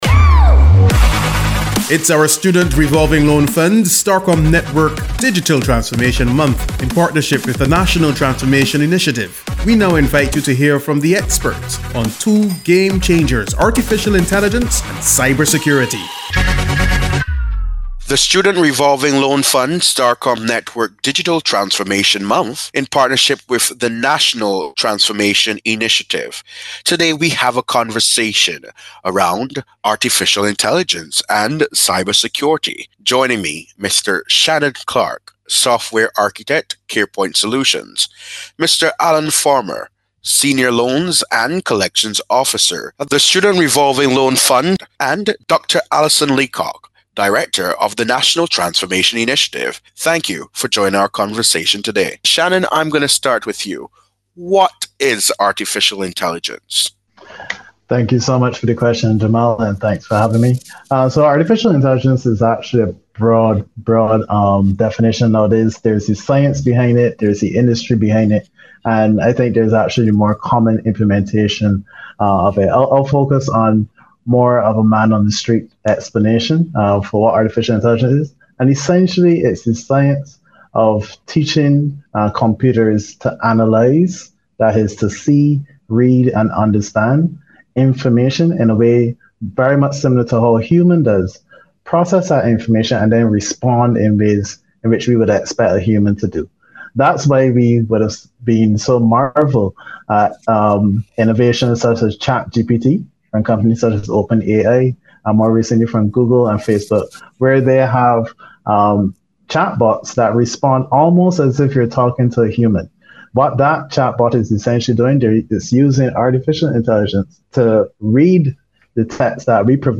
Digital Transformation Discussion- Artificial Intelligence and Cybersecurity